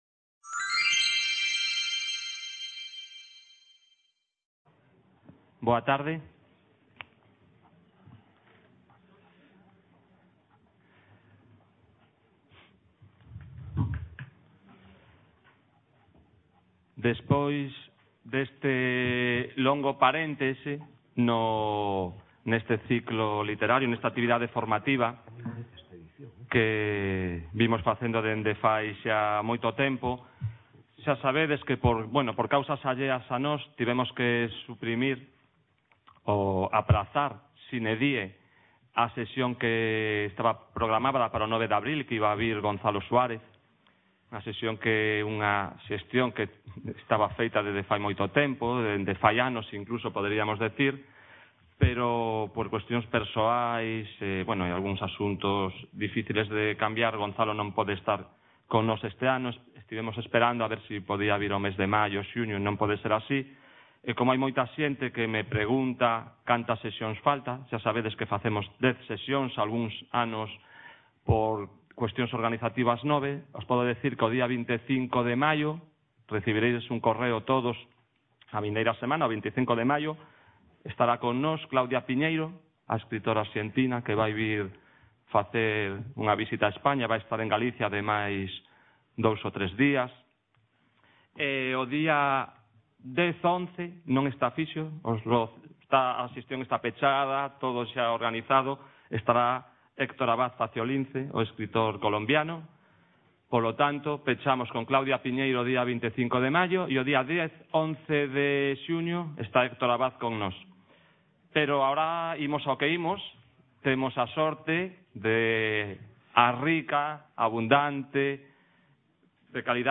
C.A. A Coruña - LA CREACIÓN LITERARIA Y SUS AUTORES. XI ENCONTROS CON ESCRITORES